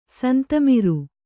♪ santamiru